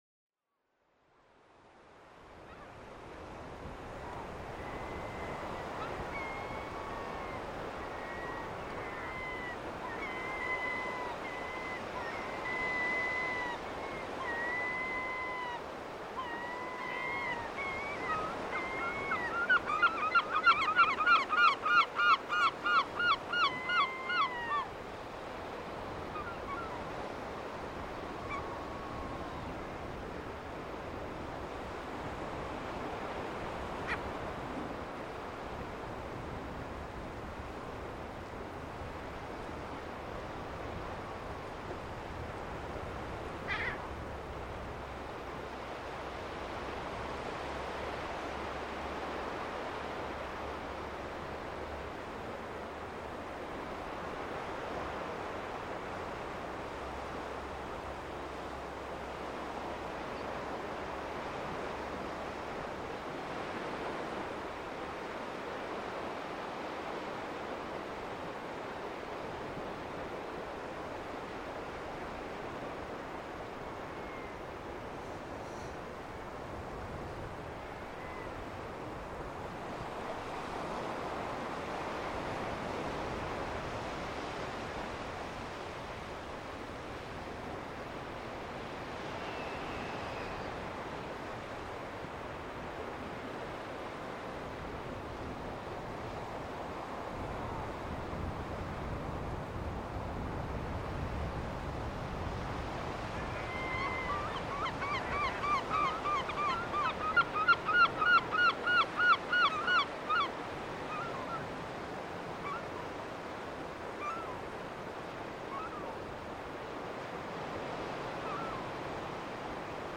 Seagulls at Amroth beach